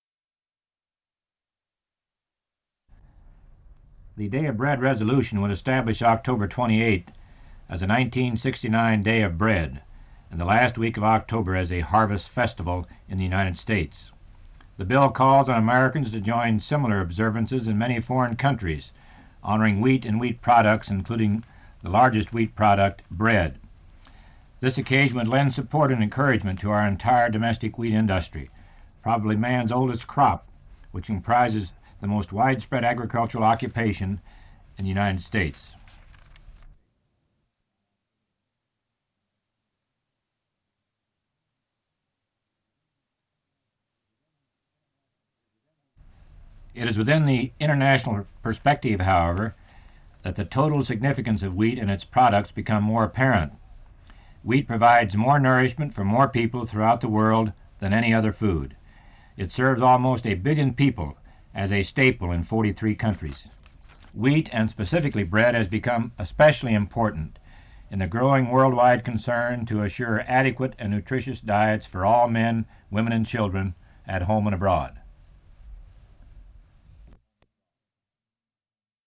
Part of Bob Dole "Day of Bread Resolution" Radio Spots